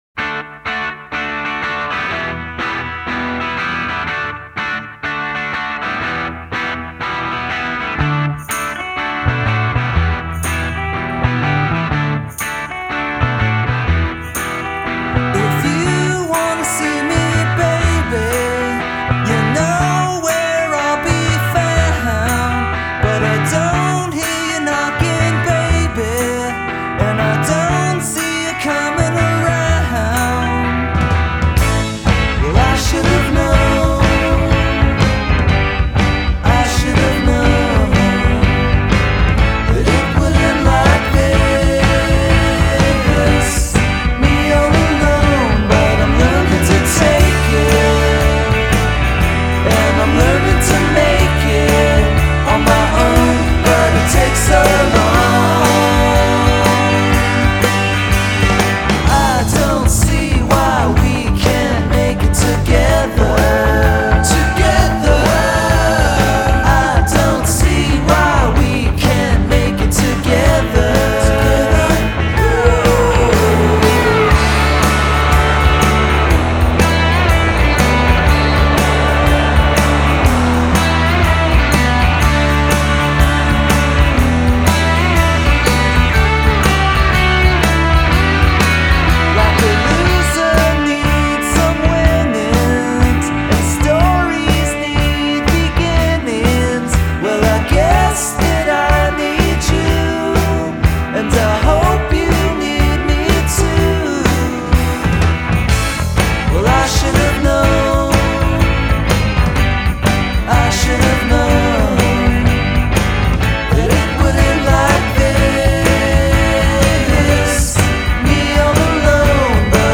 indie-music